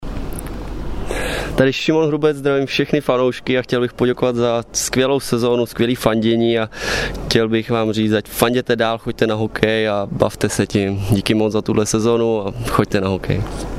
VZKAZ VŠEM FANOUŠKŮM HORÁCKÉ SLAVIE OD ŠIMONA HRUBCE SI PŘEHRAJTE